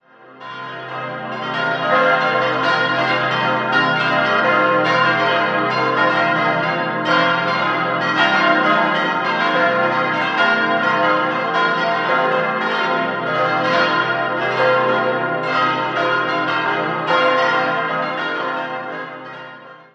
Unter Einbeziehung des alten spätgotischen Turmes wurde 1956 ein neues, geräumiges Kirchenschiff an der Pfarrkirche Eisenharz errichtet. 6-stimmiges Geläute: c'-es'-f'-g'-b'-c'' Alle Glocken wurden am 28. März 1950 von der Gießerei Hahn in Landshut gegossen und haben ein Gewicht von rund 5.250 kg.